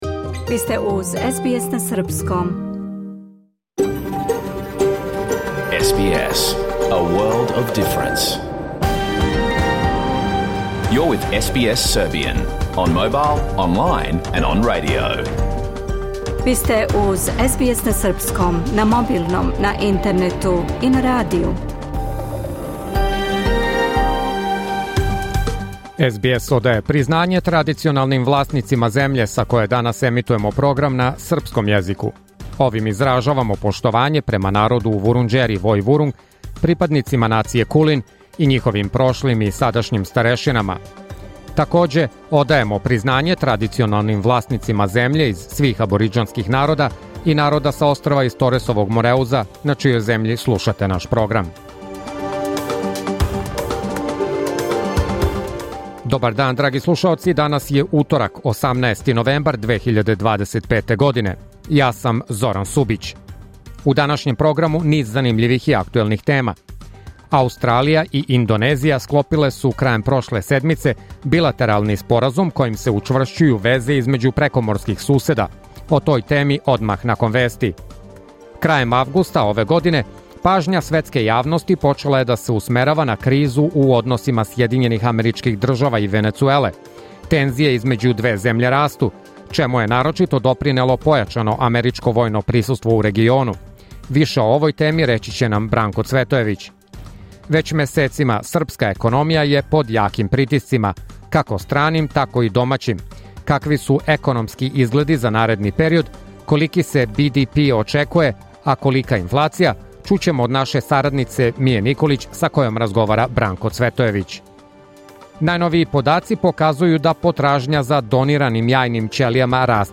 Програм емитован уживо 18. новембра 2025. године